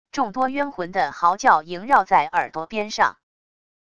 众多冤魂的嚎叫萦绕在耳朵边上wav音频